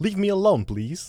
Voice Lines / Dismissive
Update Voice Overs for Amplification & Normalisation
leave me alone please.wav